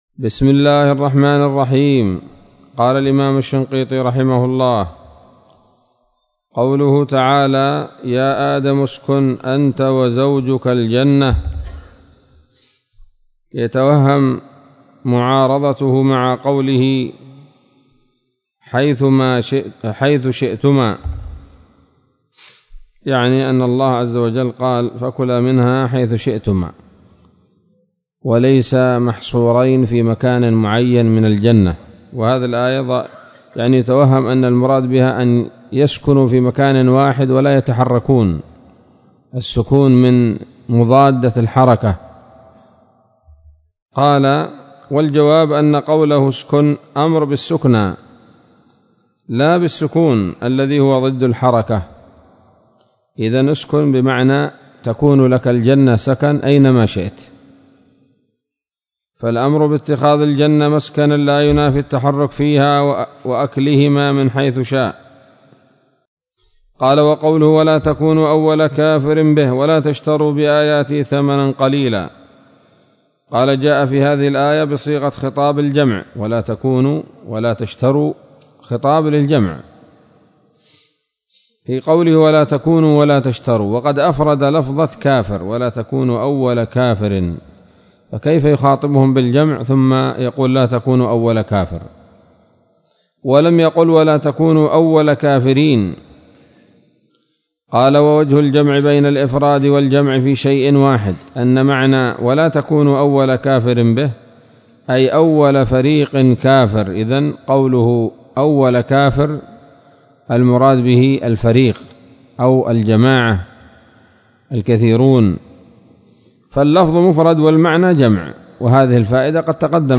الدرس التاسع من دفع إيهام الاضطراب عن آيات الكتاب